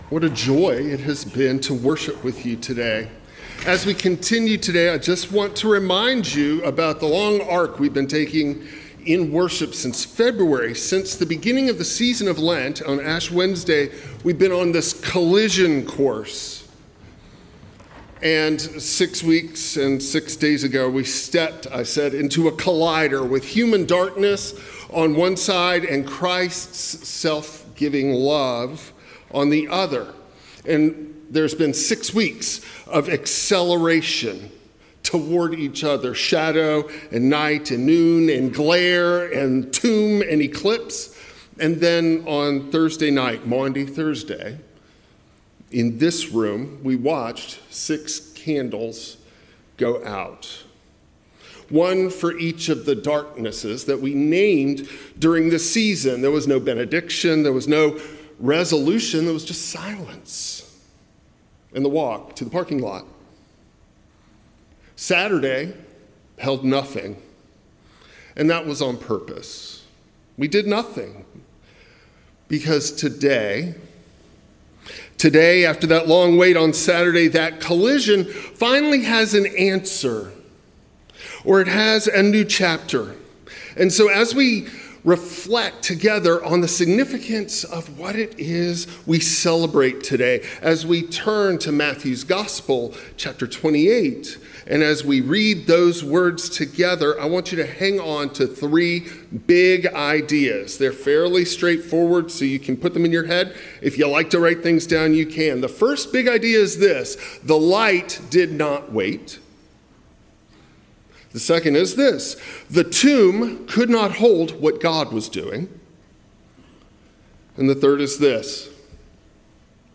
An Easter Sermon about why the resurrection broke before anyone was ready — and what that means for your Saturdays.